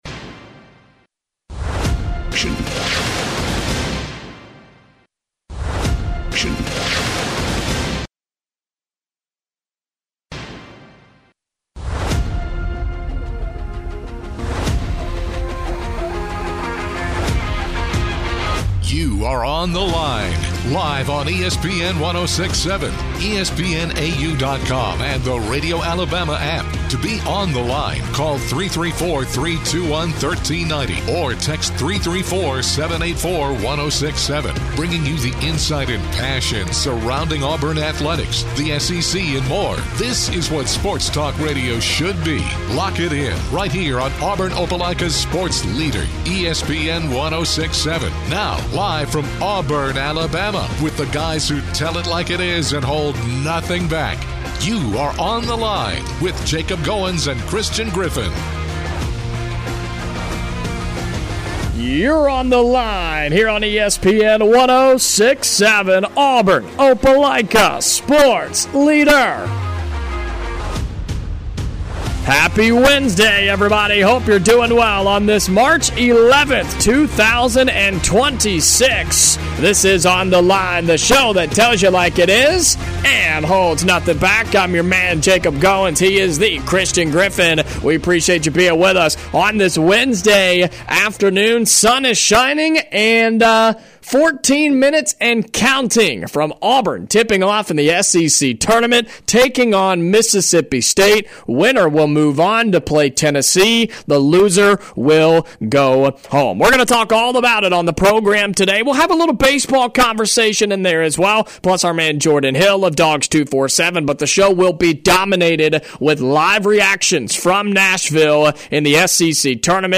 On todays episode, the boys react live to Auburn's first round basketball against Mississippi State.